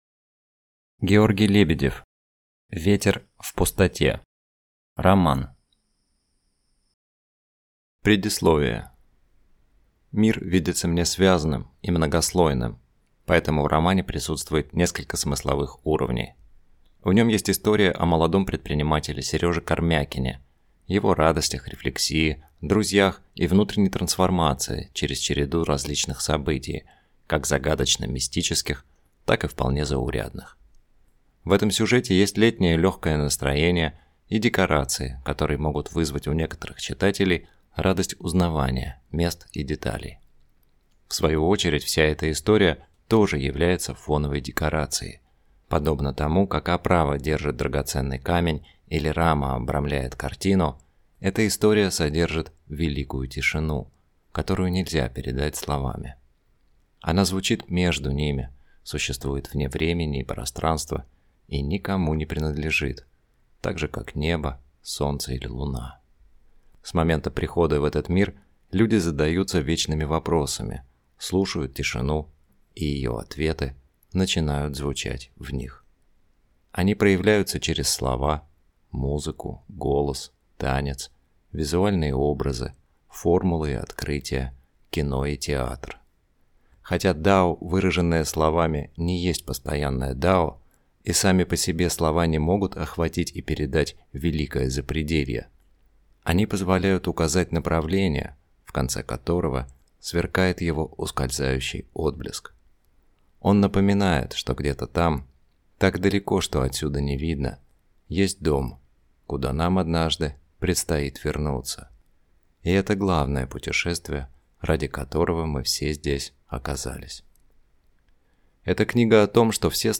Аудиокнига Ветер в пустоте | Библиотека аудиокниг